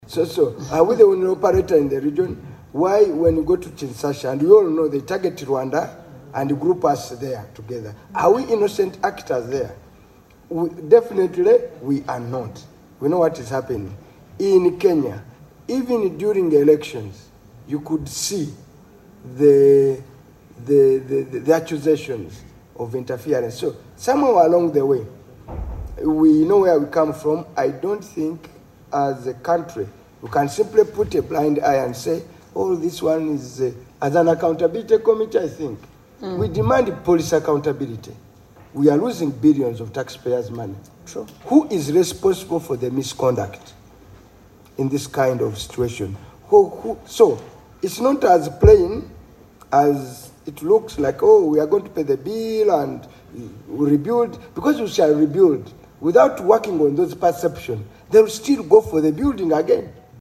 Appearing before the Public Accounts Committee - Central Government (PAC - Central) on Wednesday, 21 May 2025 at Parliament House, the Ministry of Foreign Affairs Permanent Secretary, Vincent Bagiire, revealed that although Uganda approached both the Kenyan government and the contractor’s insurance provider for compensation, neither has taken responsibility.
Butambala County MP, Hon. Muhammad Muwanga Kivumbi, the committee chairperson, raised deeper concerns about Uganda’s perception in the region.
AUDIO: Hon. Muhammad Muwanga Kivumbi
Kivumbi on perception.mp3